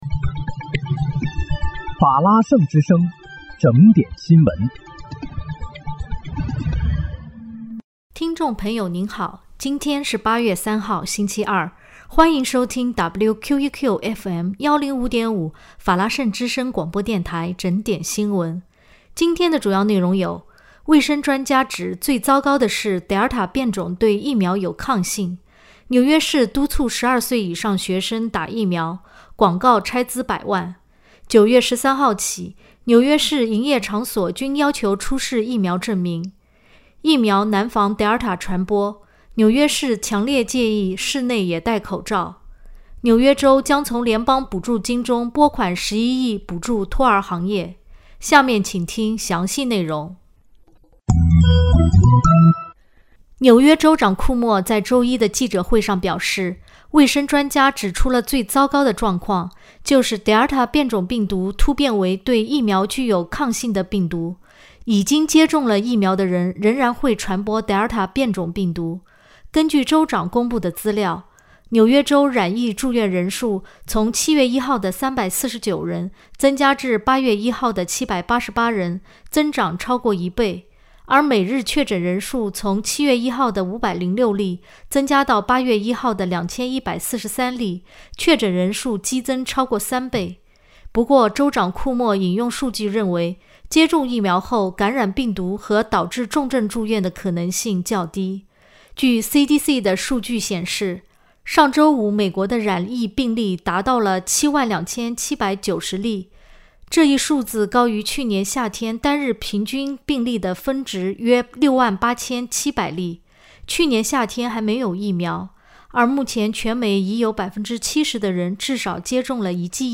8月3日（星期二）纽约整点新闻